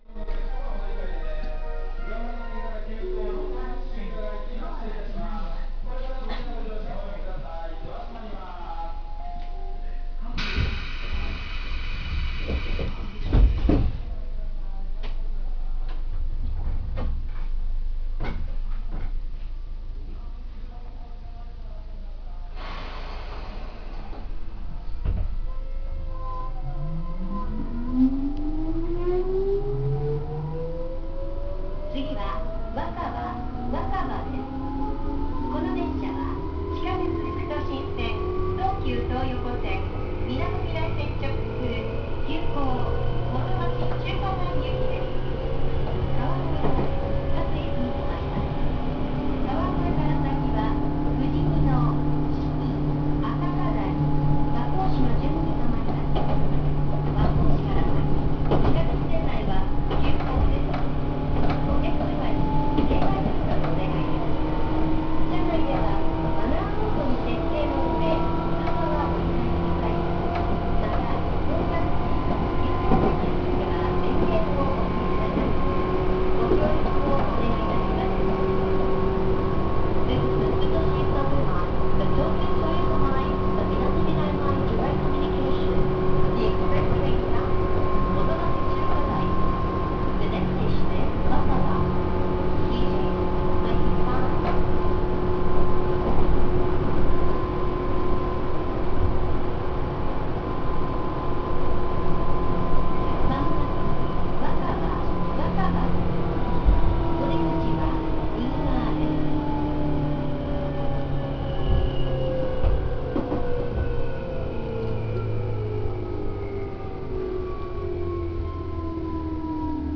・9000系更新車走行音
【東上線】坂戸〜若葉（2分21秒：769KB）
副都心線〜東横線で唯一のチョッパ車となった9000系。車内は新車同然になっていますが走行音は少々他の車両と比べて賑やかです。
東武・メトロ・東急全ての自動放送に対応。